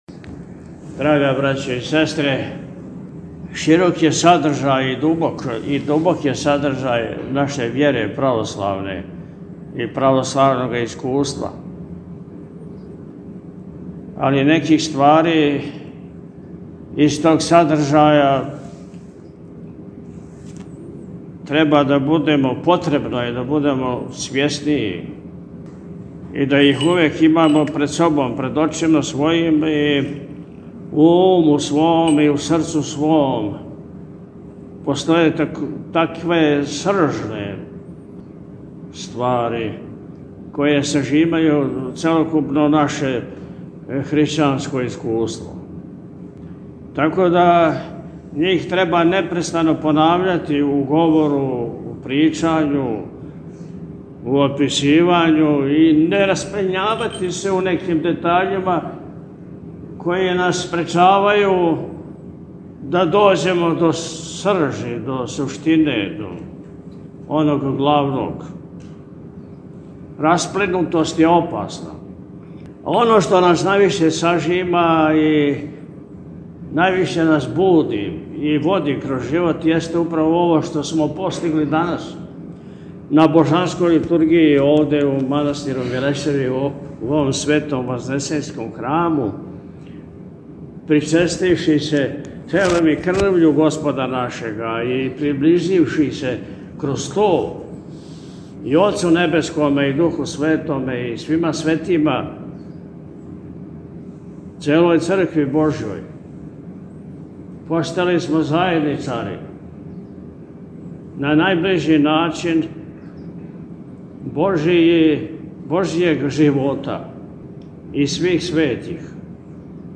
На Теодорову суботу, 8. марта 2025. године, Његово Високопреосвештенство Архиепископ и Митрополит милешевски г. Атанасије служио је Свету архијерејску Литургију у Вазнесењском храму манастира Милешеве.
Mileseva-Teodorova-subota.mp3